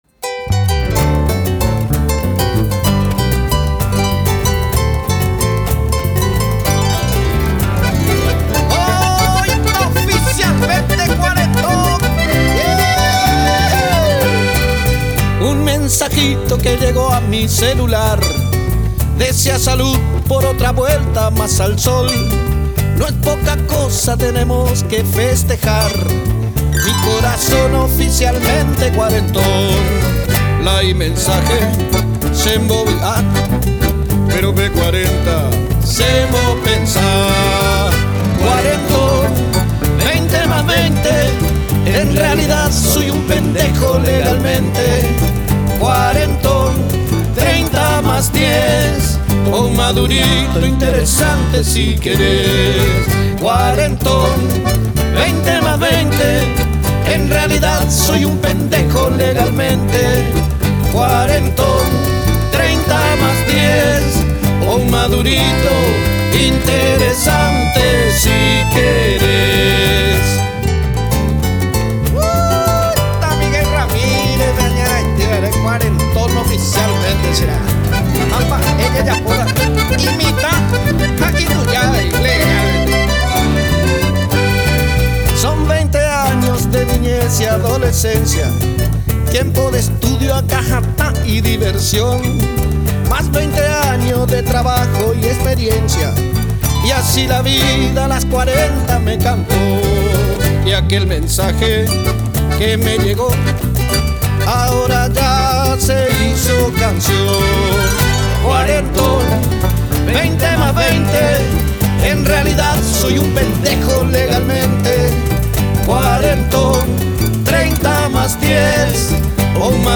El ritmo de esta composición musical es una polka kyre’y
arpa
voz y guitarra
acordeón
bajo eléctrico
percusión
teclados y segunda voz